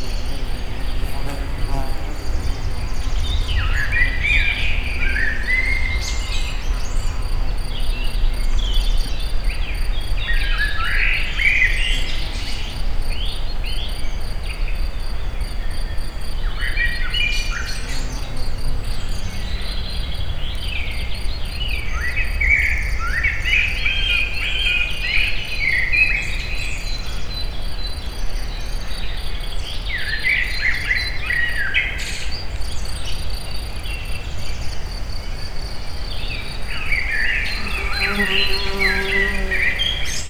Directory Listing of /_MP3/allathangok/termeszetben/rovarok_premium/
kozelrolis_egerturistahaz00.40.WAV